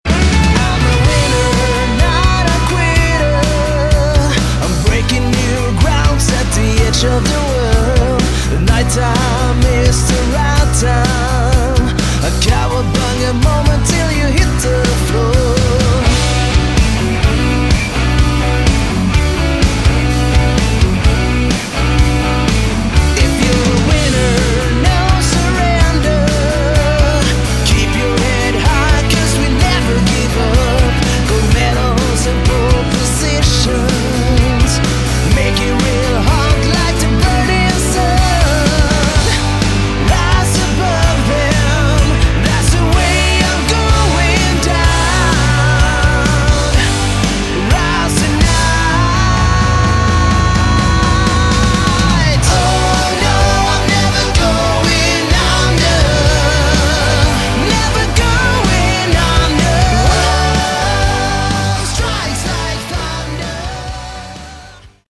Category: AOR / Melodic Rock
lead vocals
guitar, background vocals
bass, background vocals
drums, background vocals